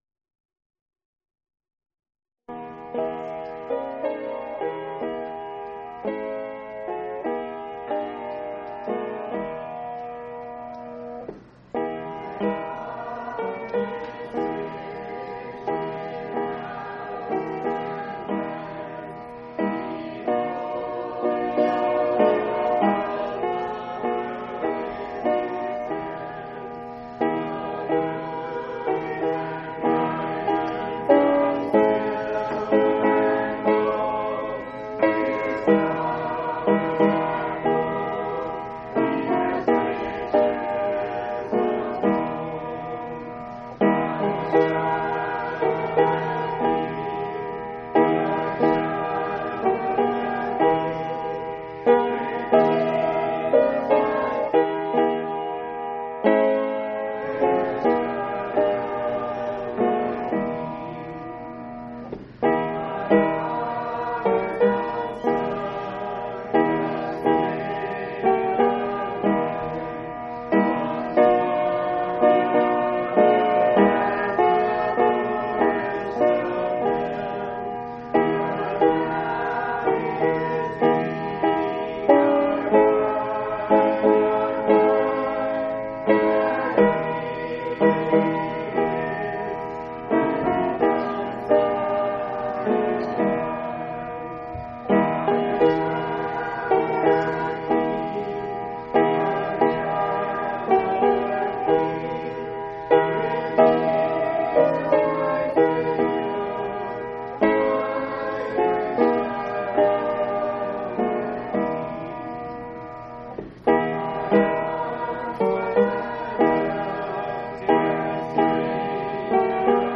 6/9/1996 Location: Phoenix Local Event